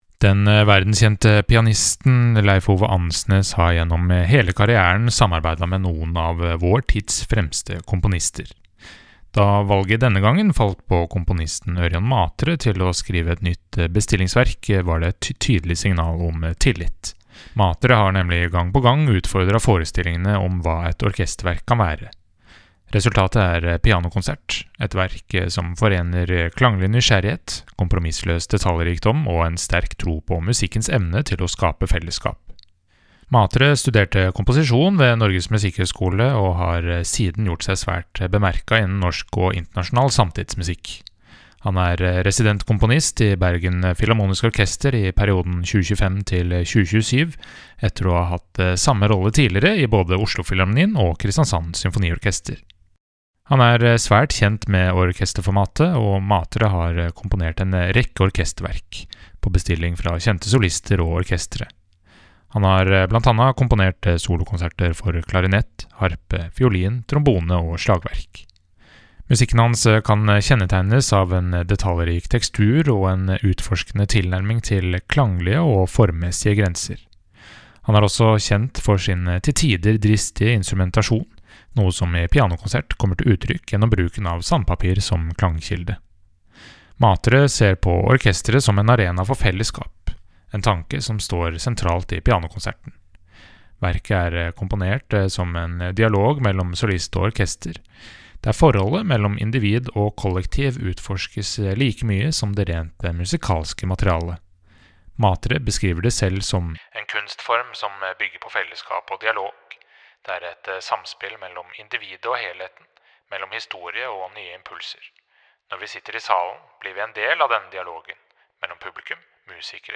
VERKOMTALE